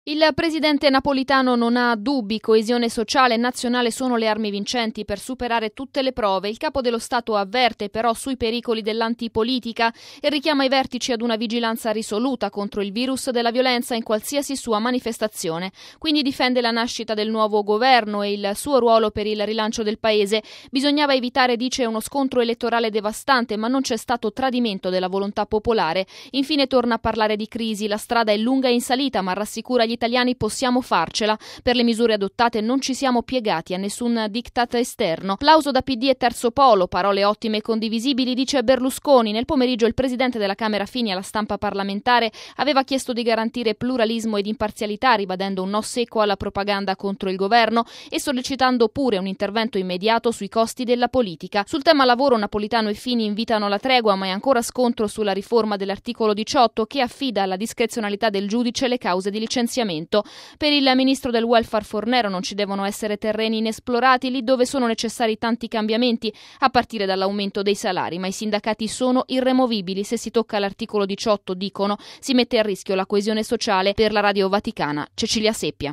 Intanto le commissioni Bilancio e Finanze del Senato hanno concluso la discussione generale sulla manovra, in tarda serata il voto dei 180 emendamenti. Resta acceso il dibattito sulla possibile riforma dell’articolo 18: i sindacati promettono battaglia. il servizio di